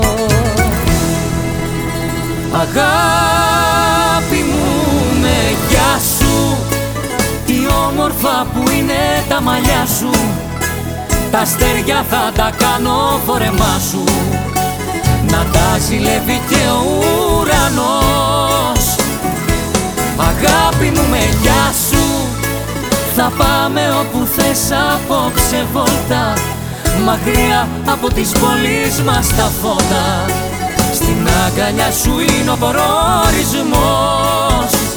Жанр: Рок